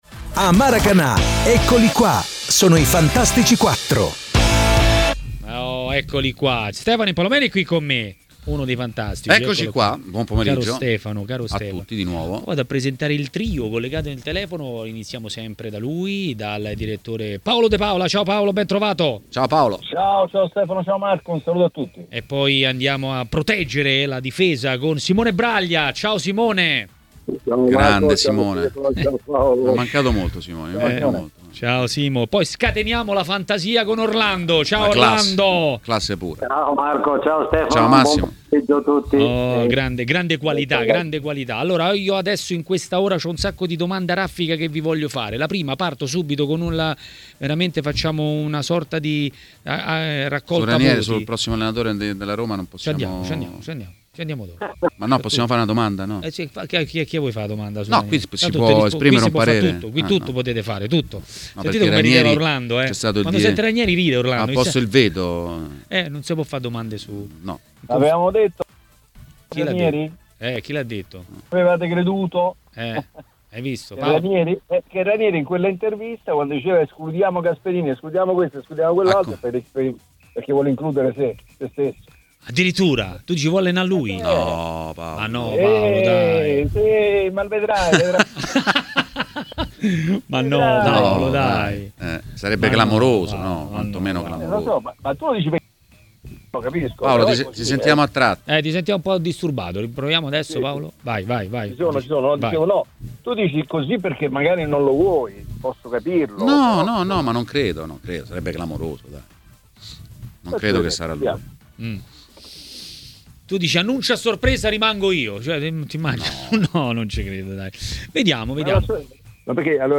ha commentato le notizie del giorno a TMW Radio, durante Maracanà.